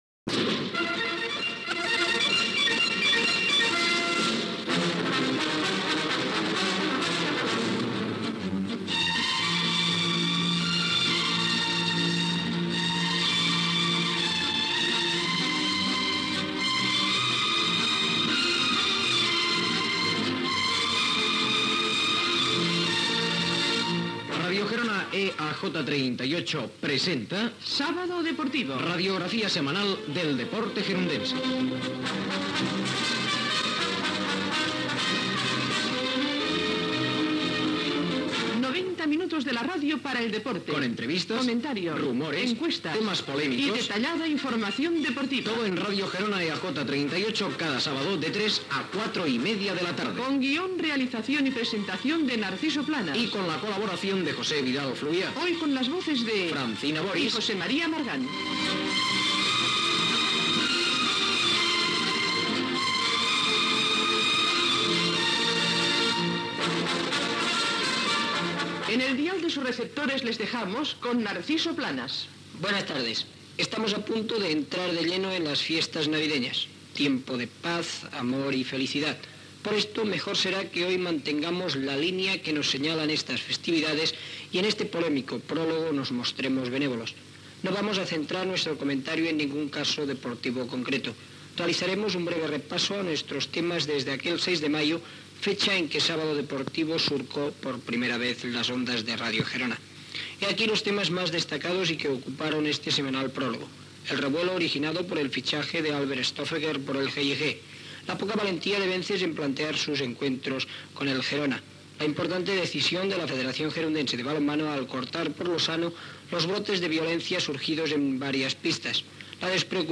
Careta del programa, temes més significatius tractats al programa des del mes de maig de 1972, sumari de continguts, publicitat, resum informatiu setmanal: la tercera setmana de l'esport gironí delebrada a Olot.
Esportiu